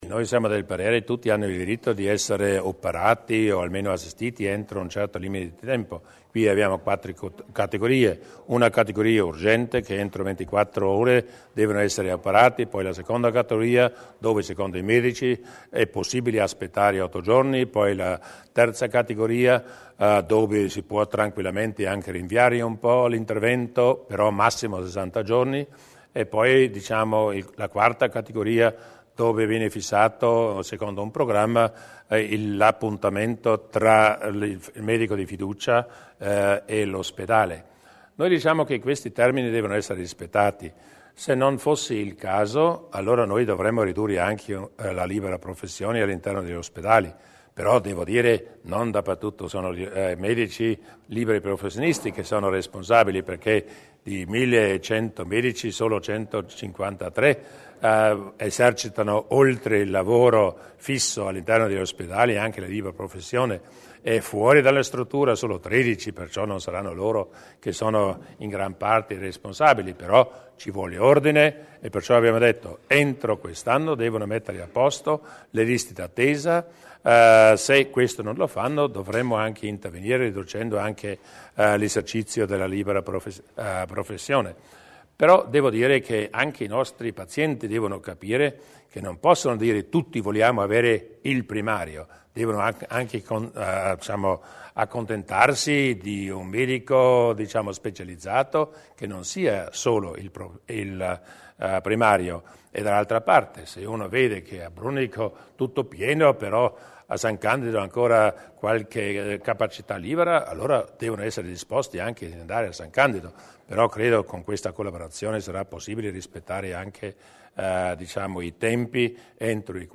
Il Presidente Durnwalder sulla riduzione delle liste d'attesa